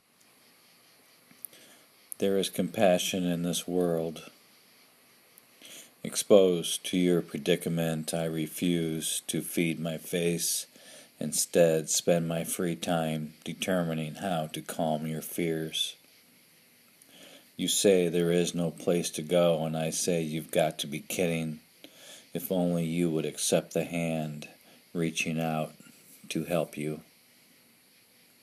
Excellent writing and a strong reading!